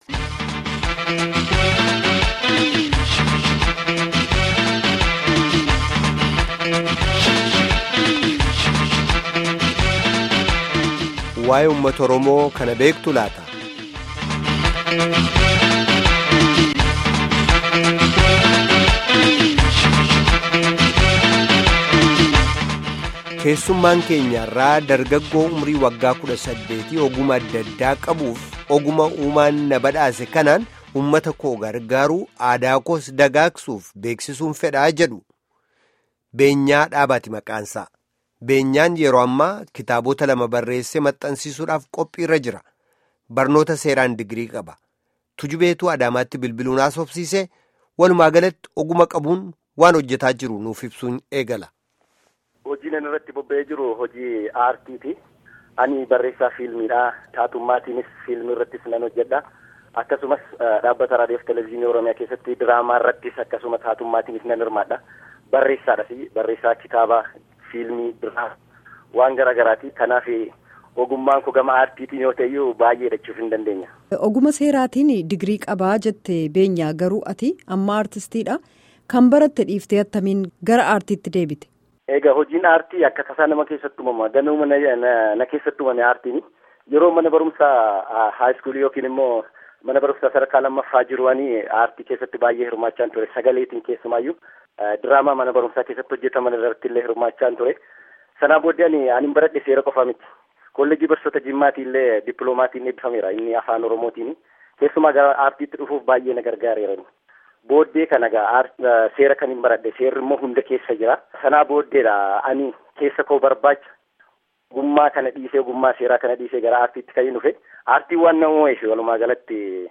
Guutummaa gaaffii fi deebii armaan gaditti caqasaa